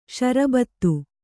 ♪ ṣarabattu